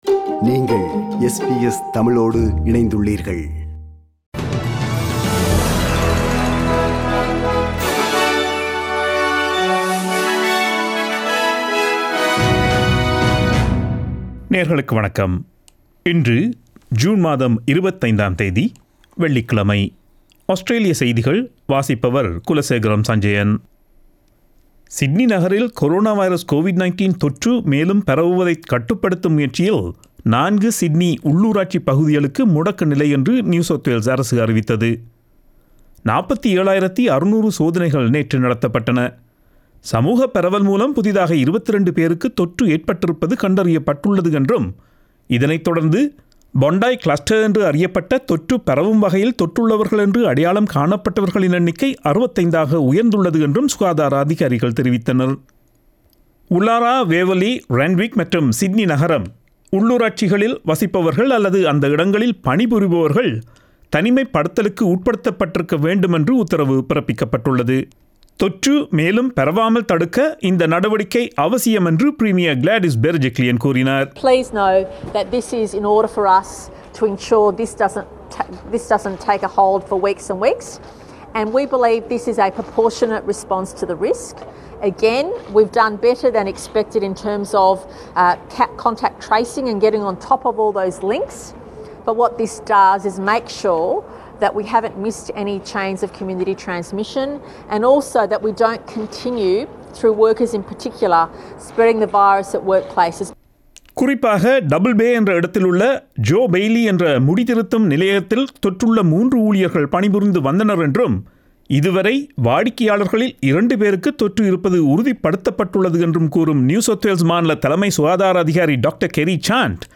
Australian news bulletin for Friday 25 June 2021.